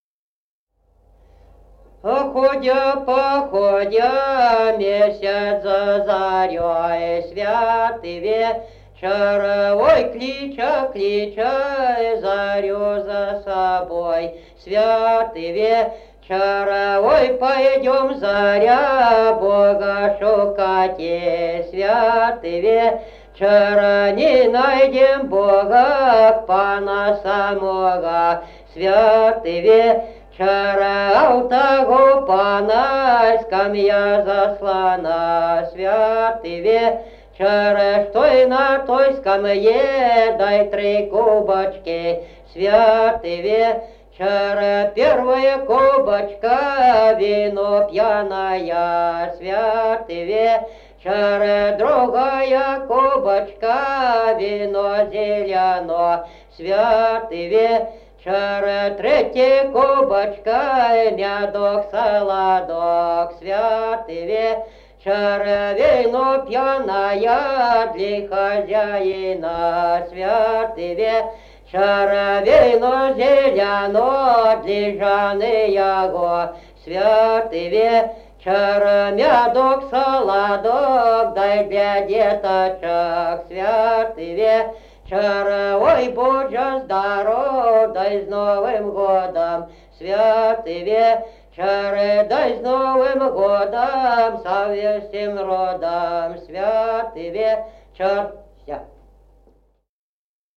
Народные песни Стародубского района «Ой, ходя, ходя», новогодняя щедровная.
д. Камень.